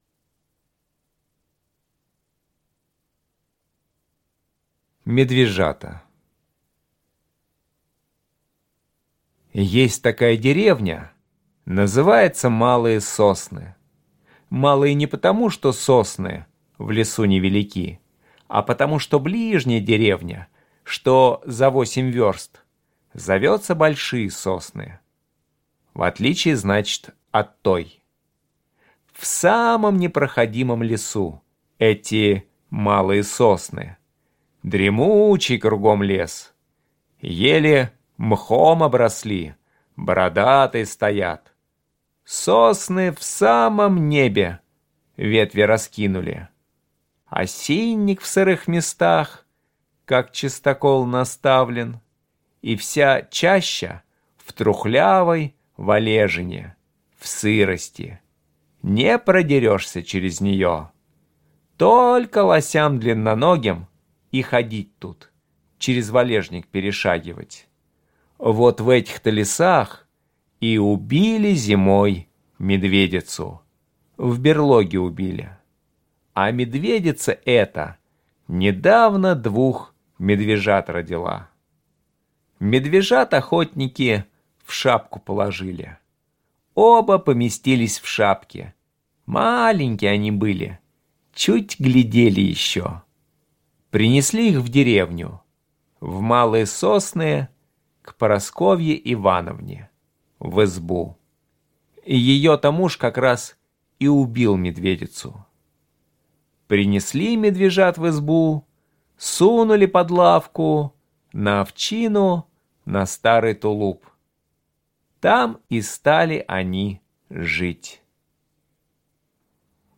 Медвежата - аудио рассказ Чарушина - слушать онлайн